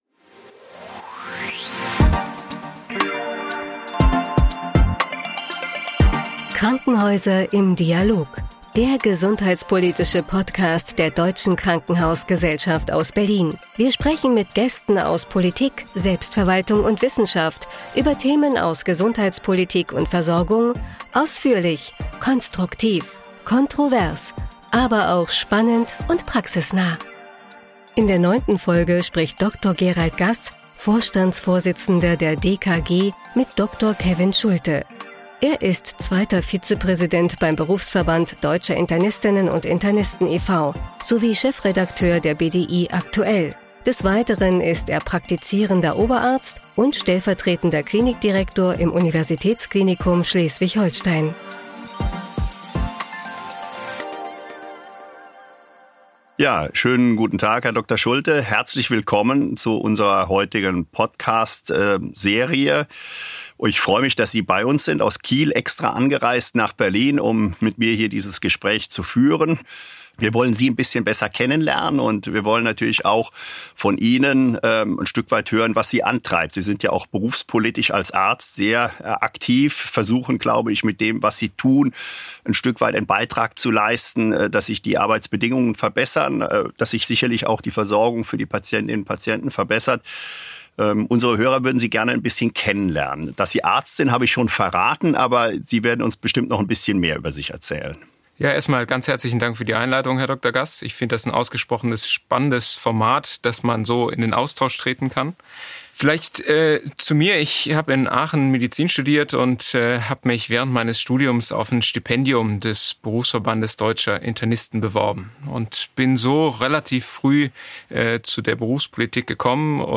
Ein besonders spannendes Gespräch für alle, die sich für Krankenhausrealität und Gesundheitspolitik interessieren.